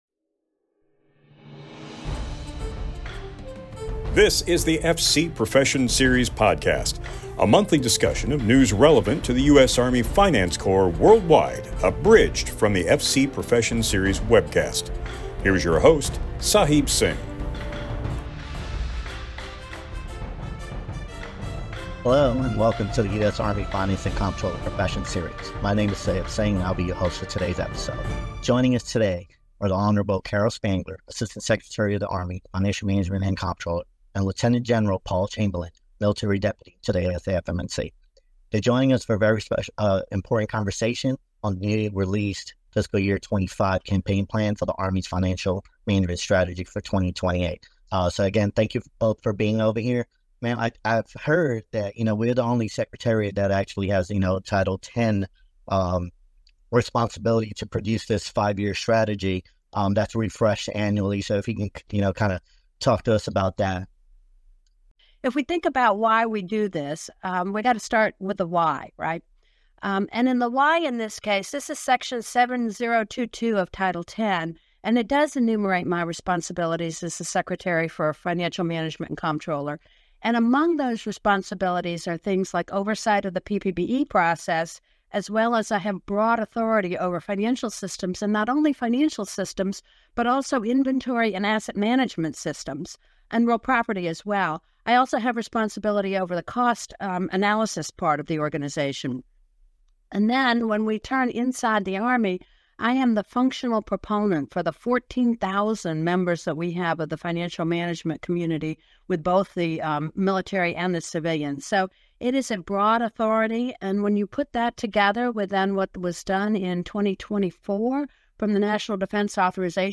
On this edition of At Your Service, we discuss the Campaign Plan Fiscal Year 2025 for the Department of the Army Financial Management Strategy 2028 with Caral E. Spangler, Assistant Secretary of the Army for Financial Management and Comptroller, and Lt. Gen. Paul A. Chamberlain, military deputy to the ASA (FM&C).